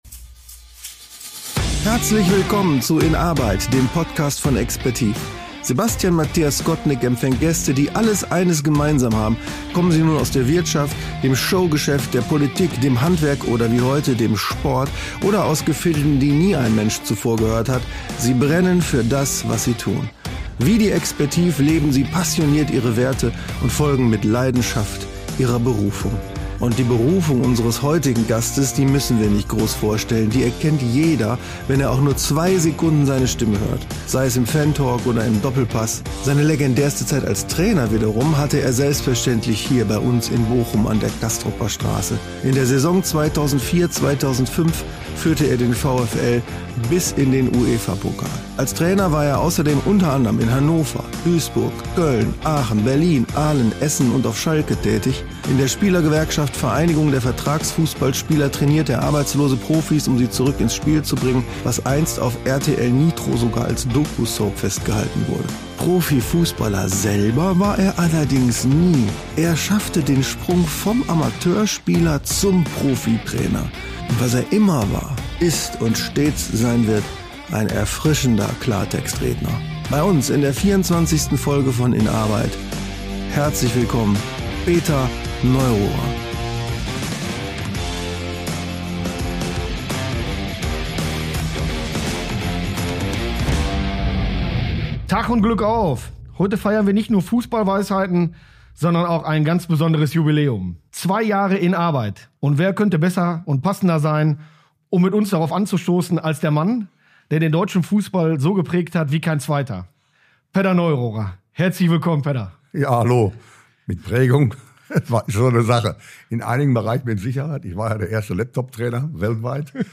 Ein Gespräch über Ehrlichkeit und Strategie, wichtige Wegbegleiter und die Kunst, die eigenen Fähigkeiten charaktervoll auszuleben.